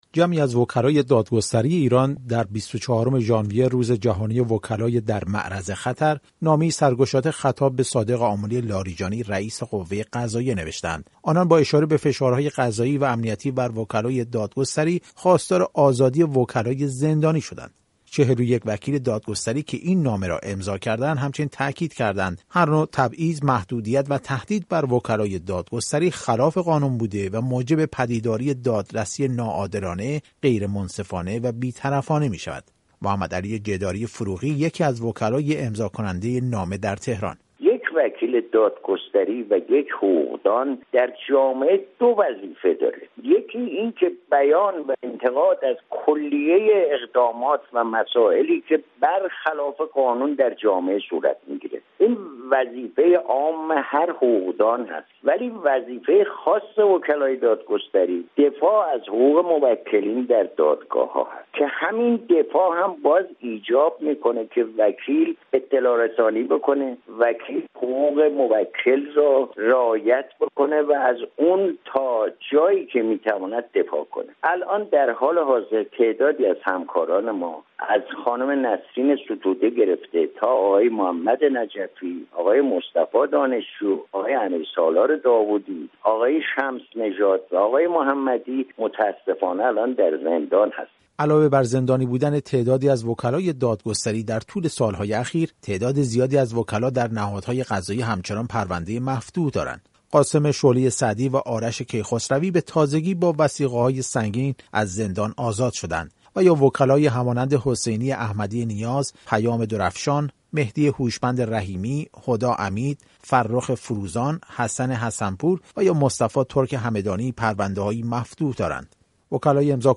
گزارش رادیو فردا درباره نامه وکلا به رئیس قوه قضاییه